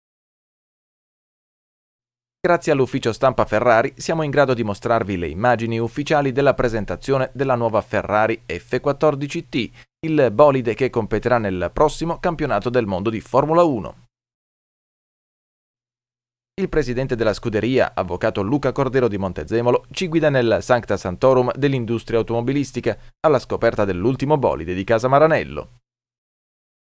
Speaker maschile.
Kein Dialekt
Sprechprobe: eLearning (Muttersprache):